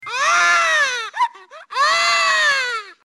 Том горько плачет